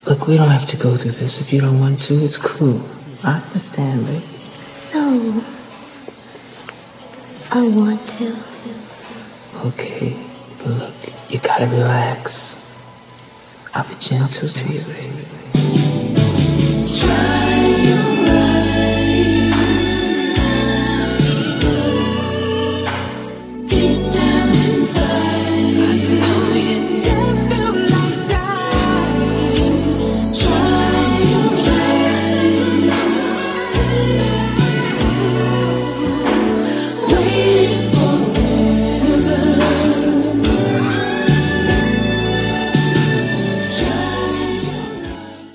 background vocals, and keyboards